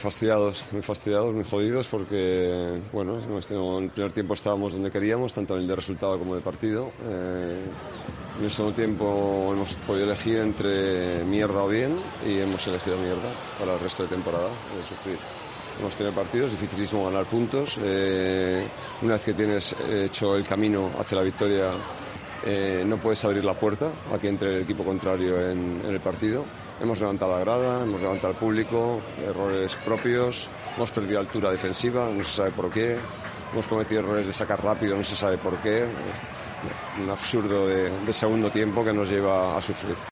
El técnico del Getafe estalla contra el equipo después del segundo tiempo que han hecho ante el Mallorca y que ha supuesto una nueva derrota.